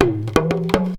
PERC 23.AI.wav